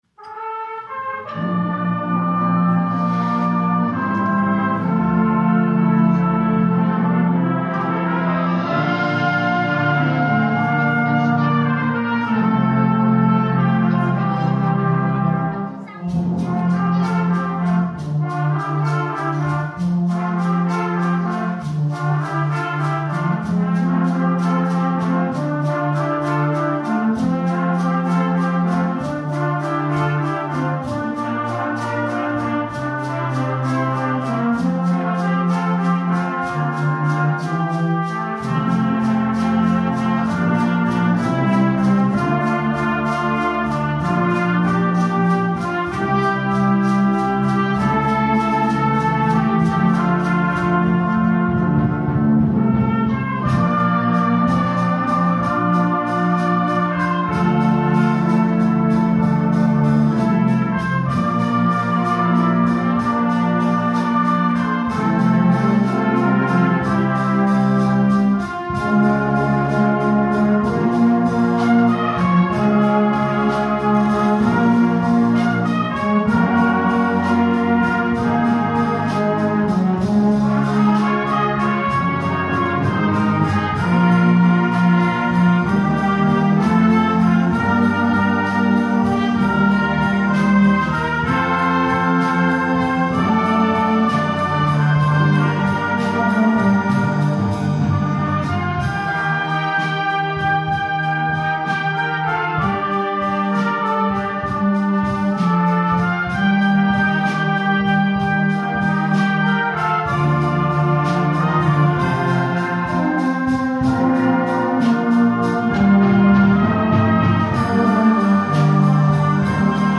Der Posaunenchor ist eine muntere Schar von ca. 20 Bläserinnen und Bläsern.
Neben der typischen Kirchenmusik mit Chorälen und dem traditionellen Liedgut für Trompeten, Posaunen und Hörner spielen wir auch klassische Stücke oder moderne Musik - besonders gerne Filmmusik, wie bei unseren letzten beiden Konzerten: egal ob aus Fluch der Karibik, Pippi Langstrumpf oder König der Löwen.
Klangproben und Konzertmitschnitte